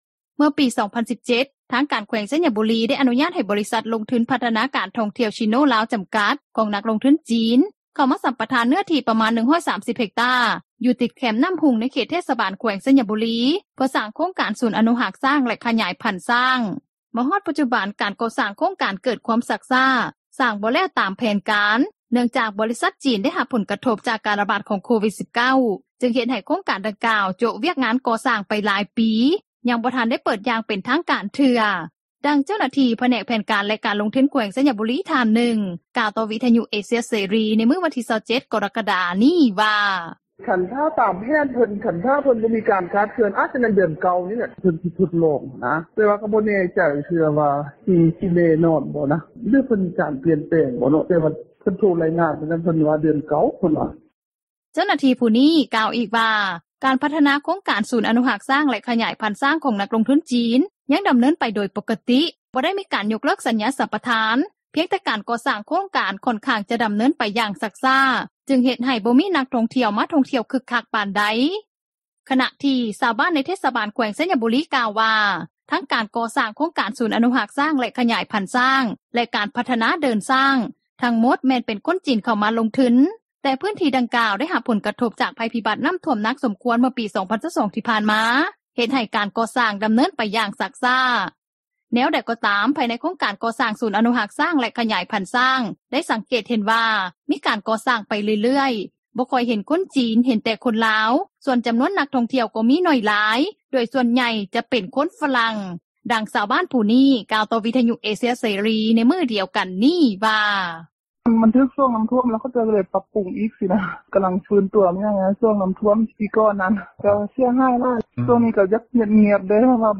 ດັ່ງຊາວບ້ານຜູ້ນີ້ ກ່າວຕໍ່ວິທຍຸ ເອເຊັຽ ເສຣີ ໃນມື້ດຽວກັນນີ້ວ່າ:
ດັ່ງຊາວລາວຜູ້ນີີ້ ກ່າວຕໍ່ວິທຍຸເອເຊັຽເສຣີ ໃນມື້ດຽວກັນນີ້ວ່າ: